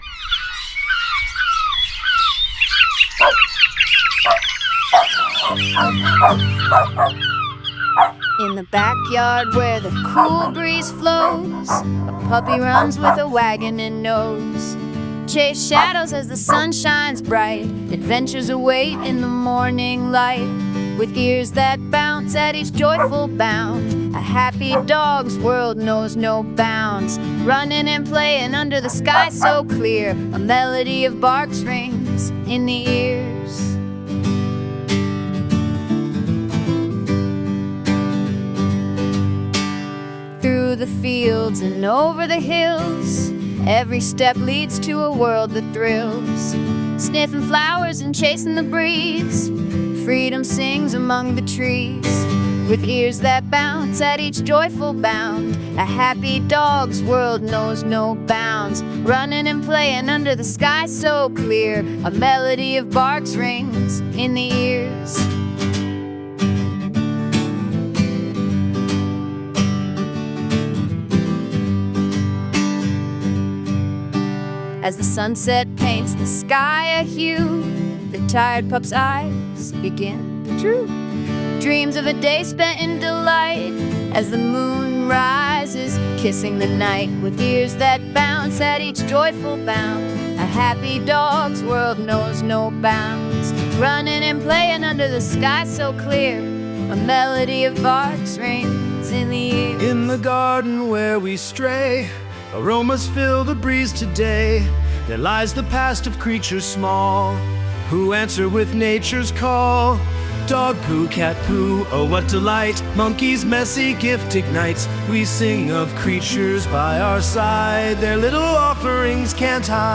A non-human piano player
HUMOUR MUSIC